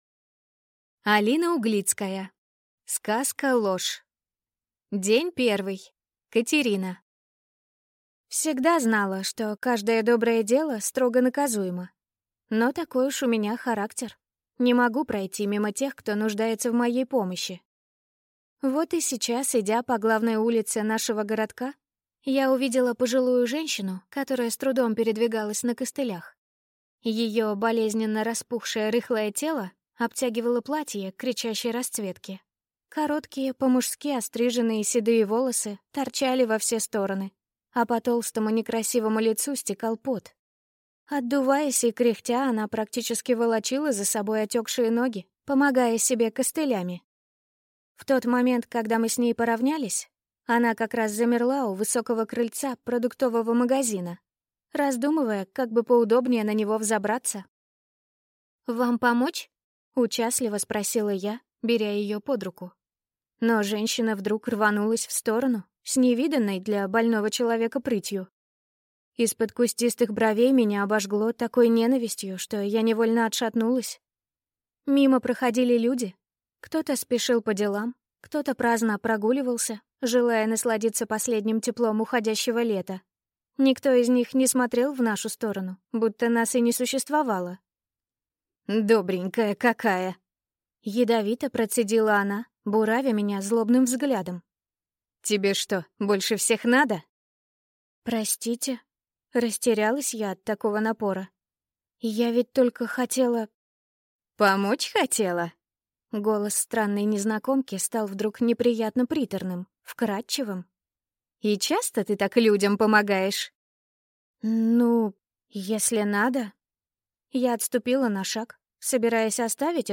Aудиокнига Сказка – ложь!